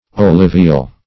Search Result for " olivil" : The Collaborative International Dictionary of English v.0.48: Olivil \Ol`i*vil\, n. [Cf. F. olivile.]
olivil.mp3